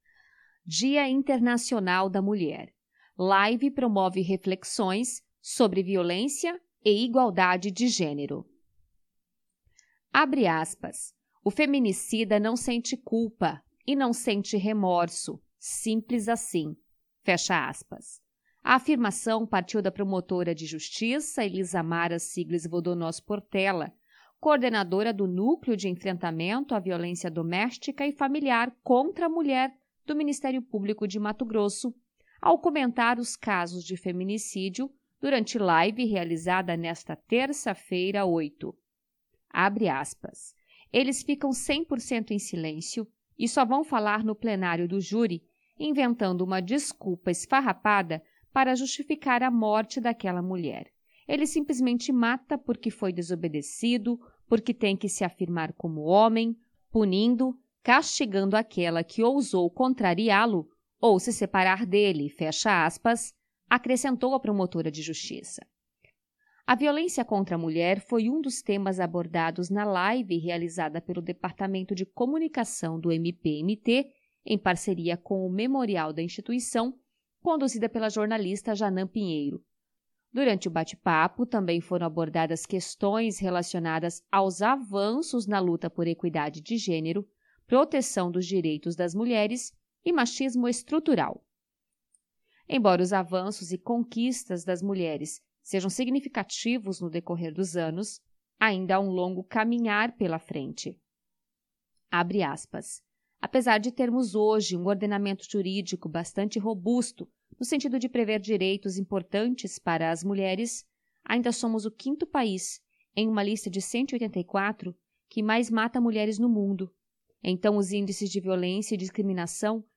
Live promove reflexões sobre violência e igualdade de gênero